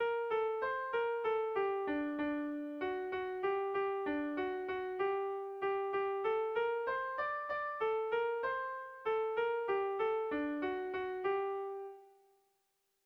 Melodías de bertsos - Ver ficha   Más información sobre esta sección
JOLES TA JOLES hasten da berez doinua eta gero dator kopla doinua.
Kopla handia